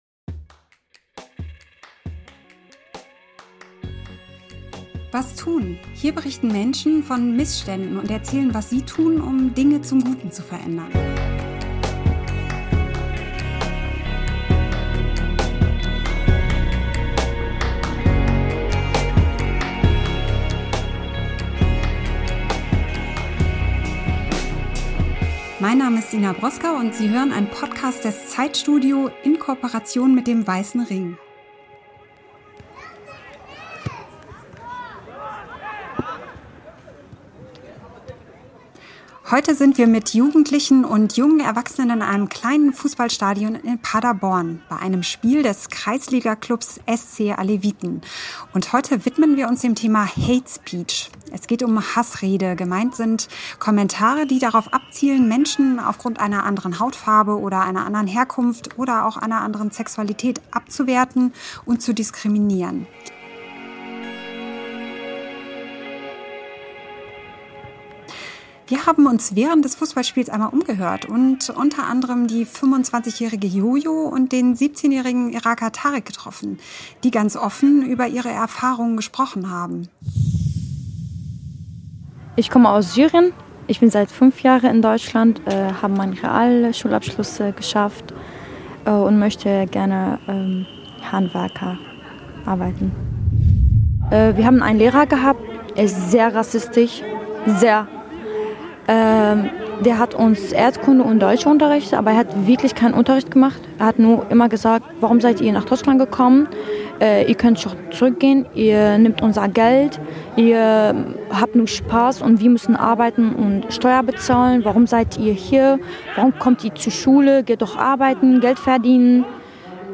Im Auftrag des Weissen Rings haben wir ein Fußballspiel des mehrfach ausgezeichneten Kreisligaclubs SC Aleviten in Paderborn besucht.